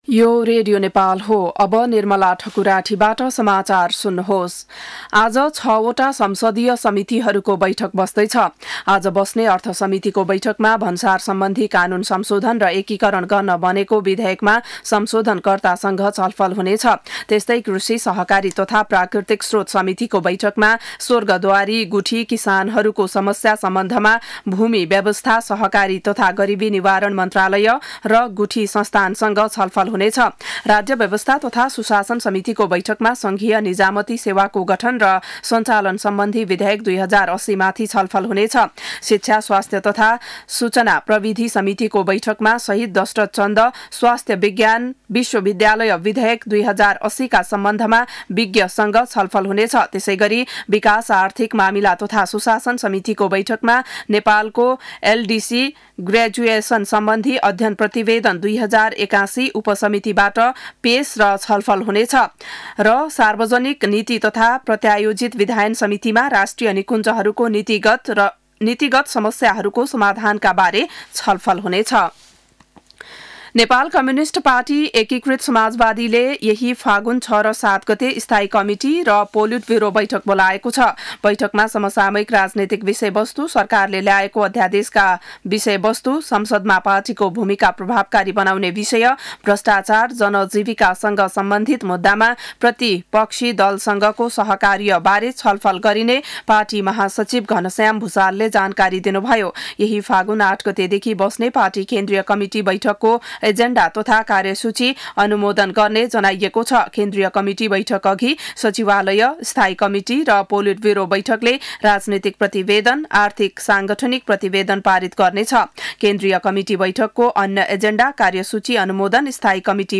बिहान ११ बजेको नेपाली समाचार : ३ फागुन , २०८१
11-am-news-1-6.mp3